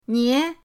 nie2.mp3